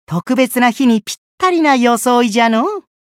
觉醒语音 特別な日にぴったりな装いじゃろ？